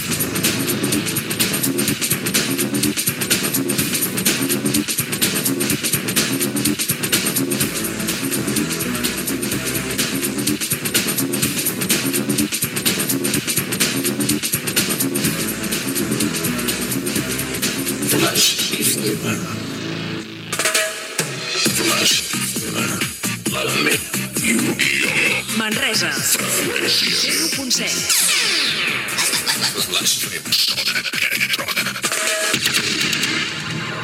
Indicatiu de la ràdio i freqüència a Manresa